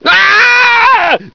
scream01.ogg